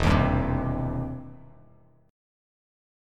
EmM7#5 chord